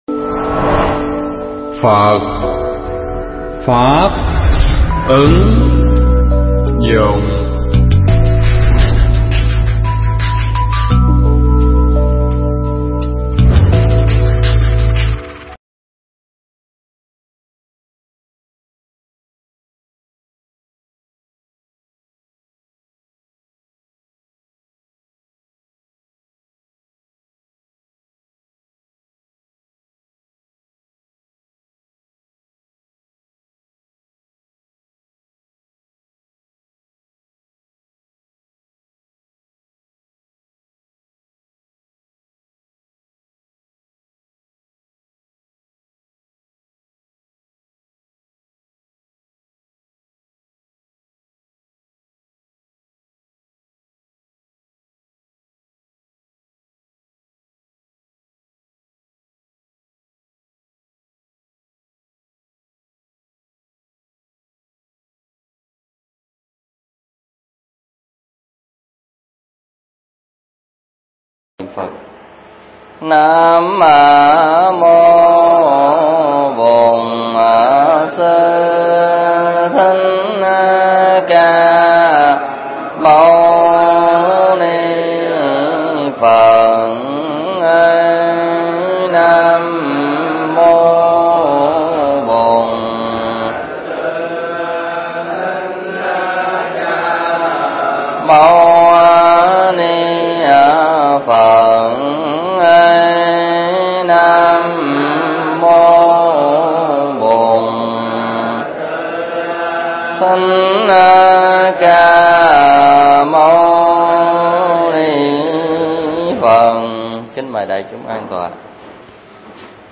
Mp3 Thuyết Pháp Kinh Pháp Bảo Đàn 12